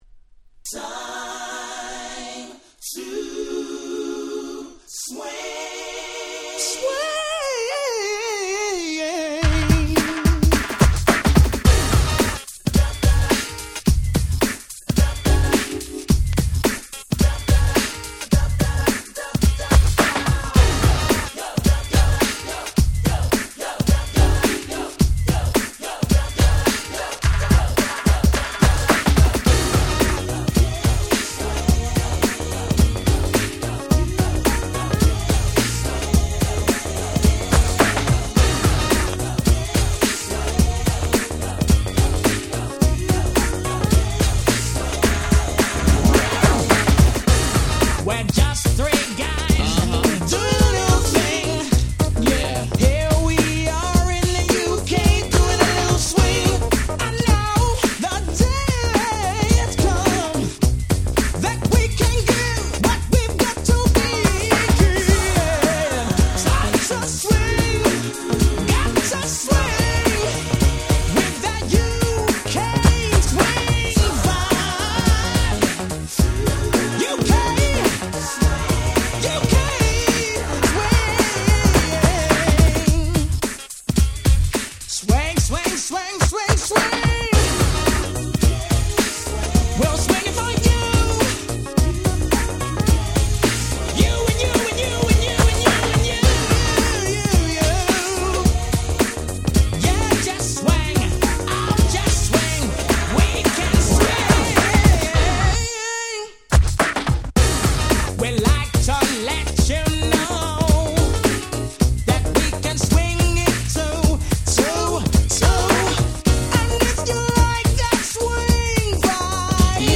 93' Super Nice New Jack Swing !!
タイトル通りまさに「UK産ニュージャックスィング」！！